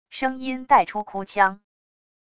声音带出哭腔wav音频